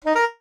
jingles-saxophone_15.ogg